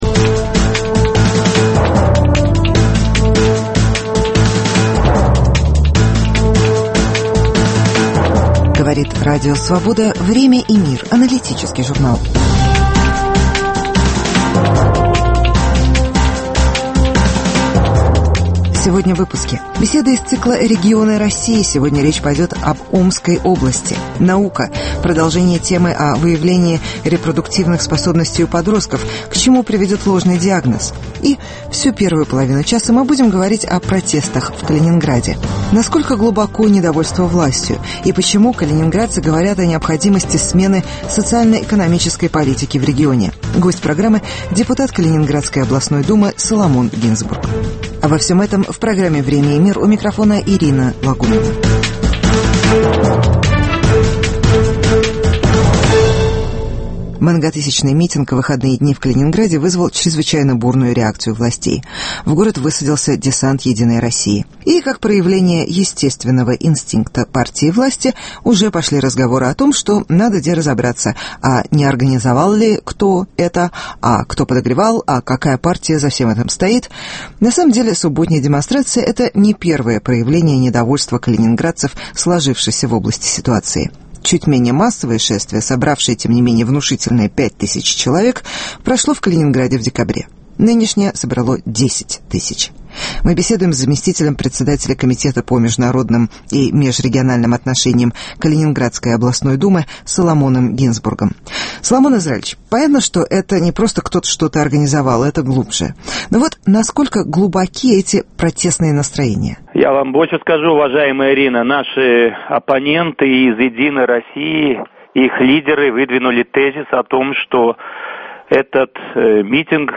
Насколько глубоки протестные настроения в Калининграде. Интервью с членом Калининградской думы Соломоном Гинзбургом.